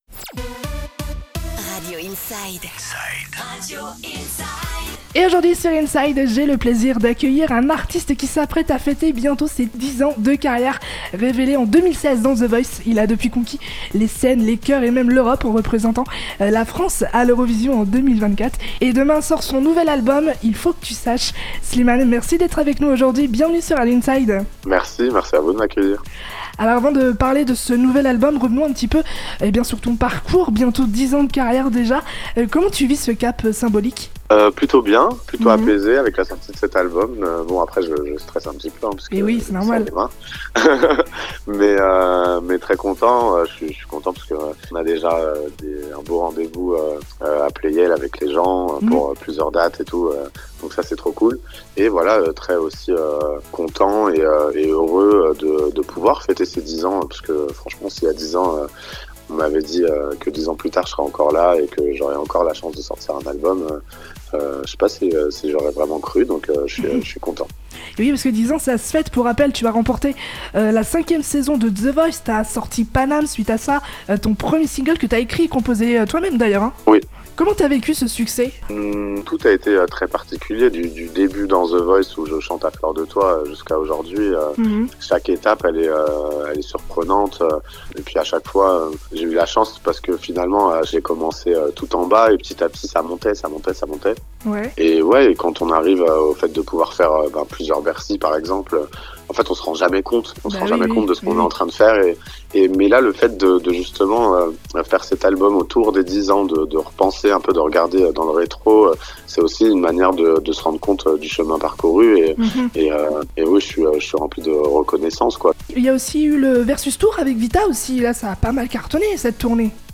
Interview de Slimane en intégralité " nouvel album : Il faut que tu saches" sur Radio Inside